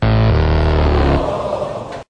fail.mp3